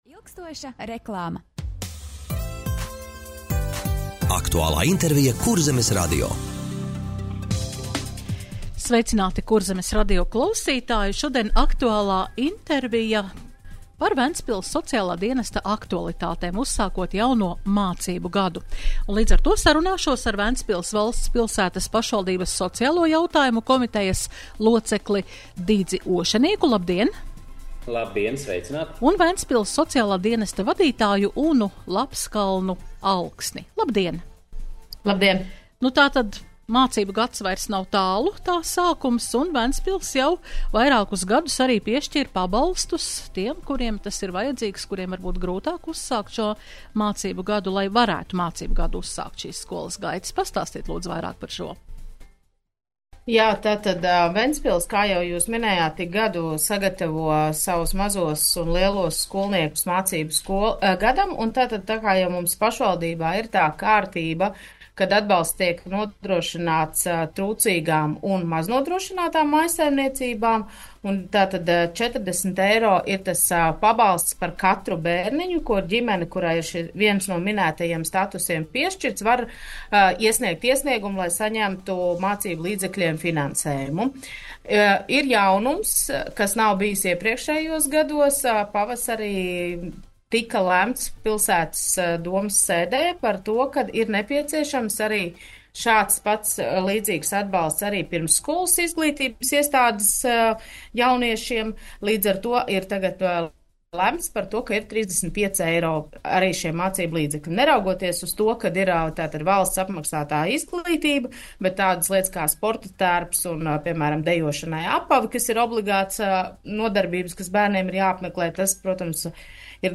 Radio saruna Ventspils Sociālā dienesta aktualitātes - Ventspils